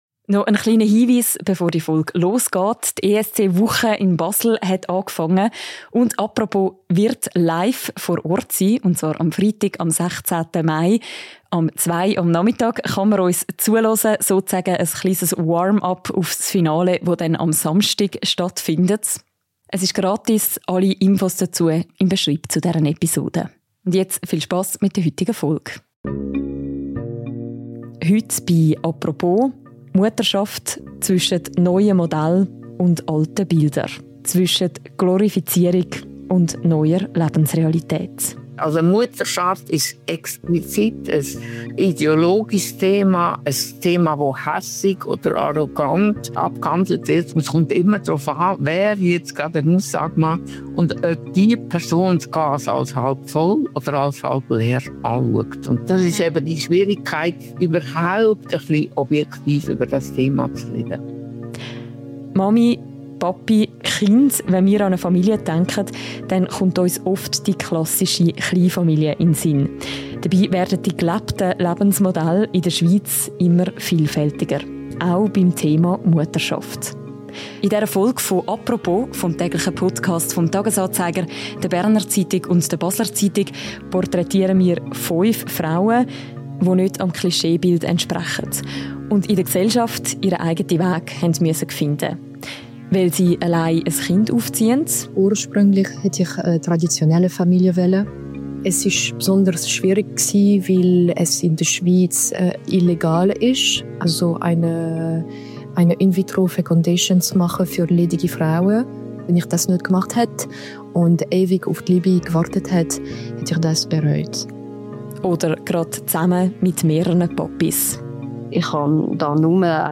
Und fünf Frauen erzählen in dieser Folge, wie sie ihren eigenen Weg in der Gesellschaft finden mussten.